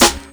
Boom-Bap Snare 92.wav